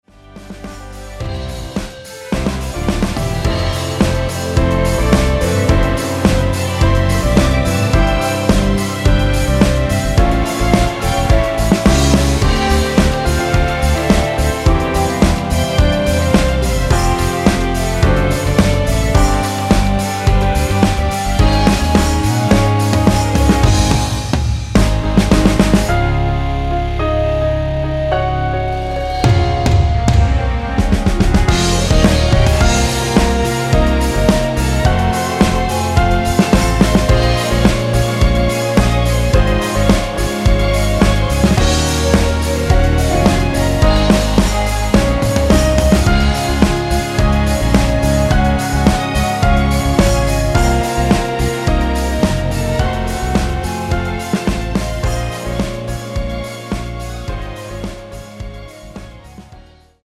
전주 없이 시작 하는곡이라 노래 하시기 좋게 2마디 전주 만들어 놓았습니다.(약 5초쯤 노래 시작)
Bb
앞부분30초, 뒷부분30초씩 편집해서 올려 드리고 있습니다.
중간에 음이 끈어지고 다시 나오는 이유는